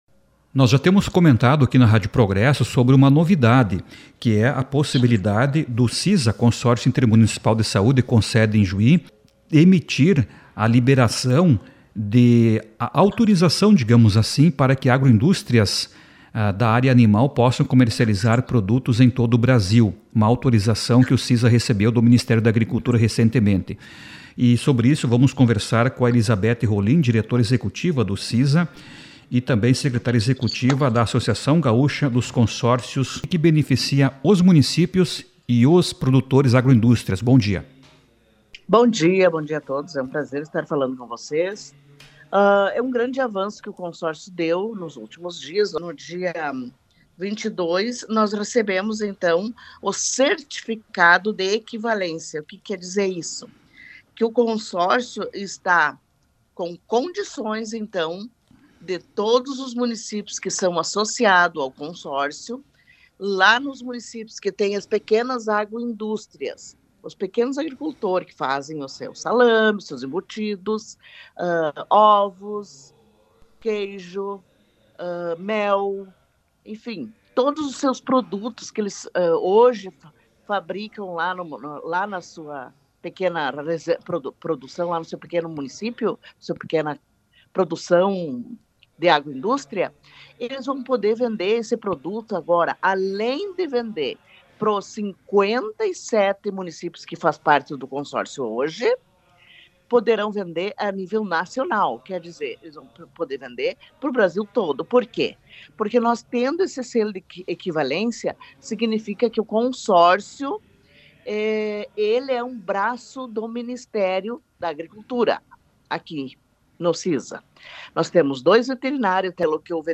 ENTREVISTA-PROGESSO-RURAL-12.10-OFICIAL.mp3